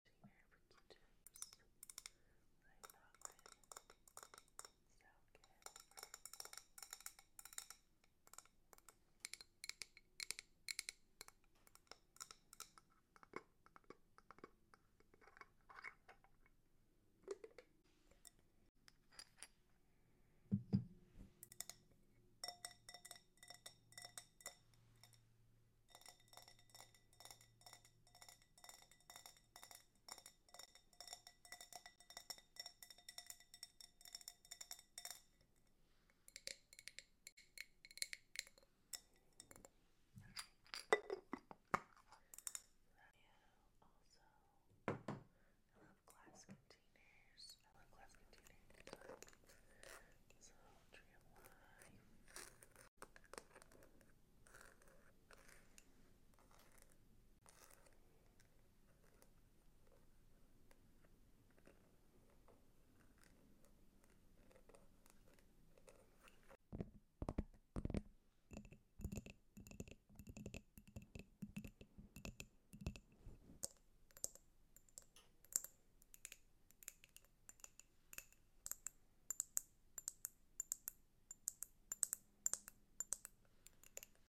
Tappy Taps To Relax On Sound Effects Free Download